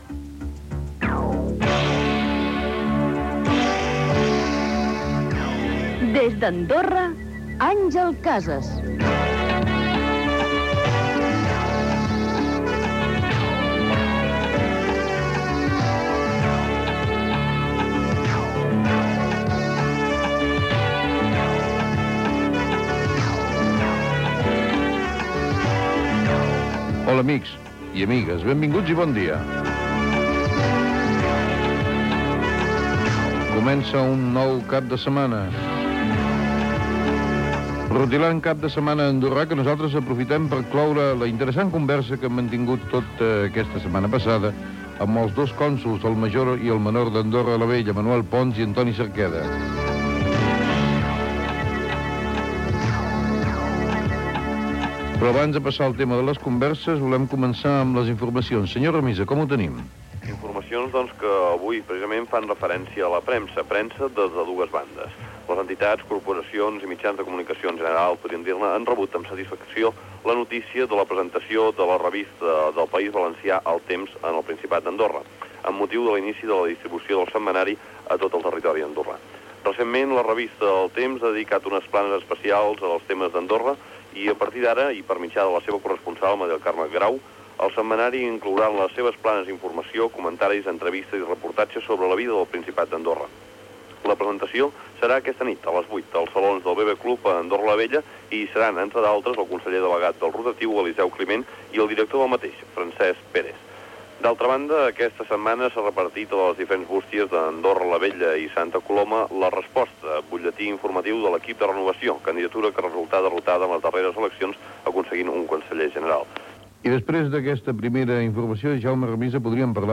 Careta del programa, presentació, sumari, informació de la premsa andorrana (el setmanari "El temps" arriba a Andorra), el temps, publicitat, tema musical
Info-entreteniment